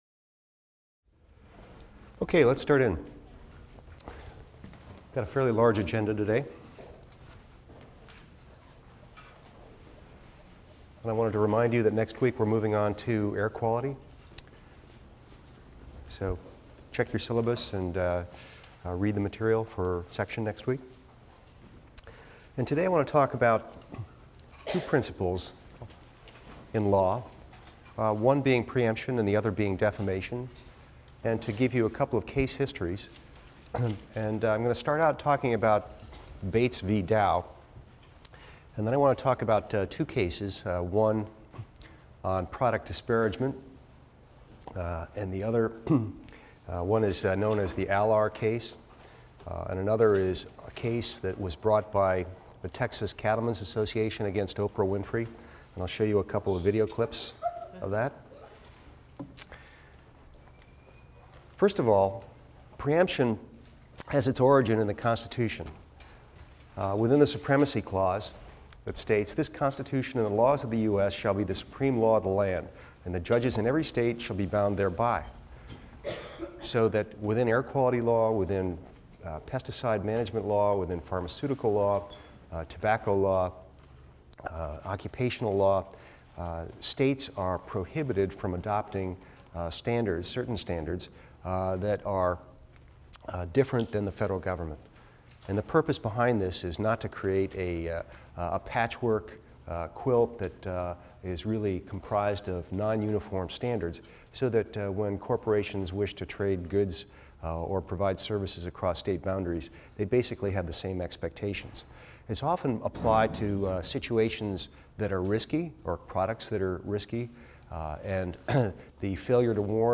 EVST 255 - Lecture 11 - Safety Claims and Free Speech: Preemption and Defamation | Open Yale Courses